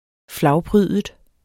Udtale [ ˈflɑwˌpʁyːðəd ]